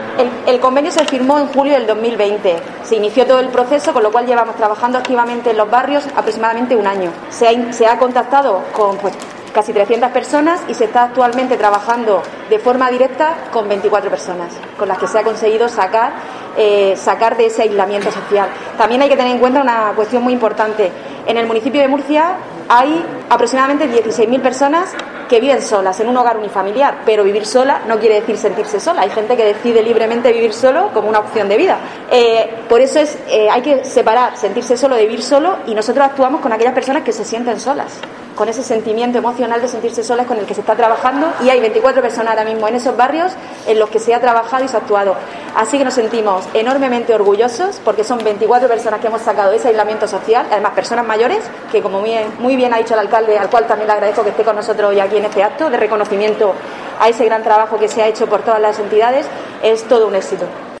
Paqui Pérez, concejala de Mayores, Vivienda y Servicios Sociales